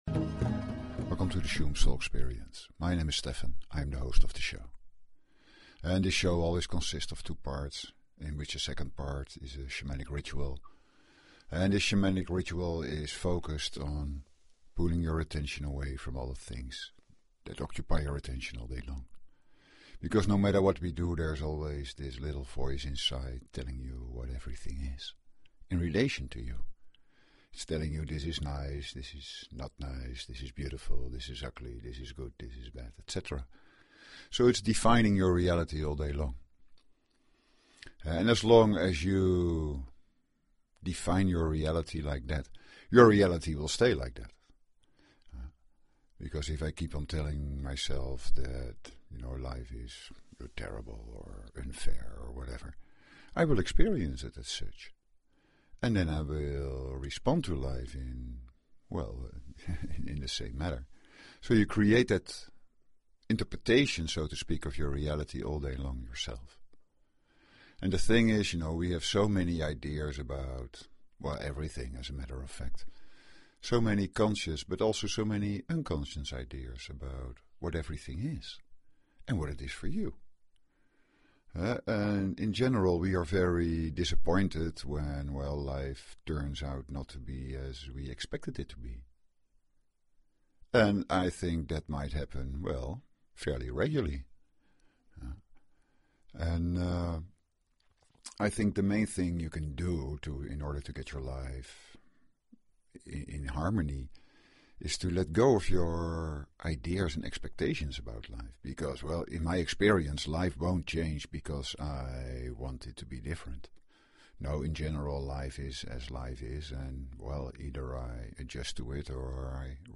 Talk Show Episode, Audio Podcast, Shuem_Soul_Experience and Courtesy of BBS Radio on , show guests , about , categorized as
The second part of the show is a shamanic meditation ritual that helps to silence all these inner voices that tell you life should be different. Lie or sit down to listen, let the sounds flow through and touch upon the inner peace that is inside of you.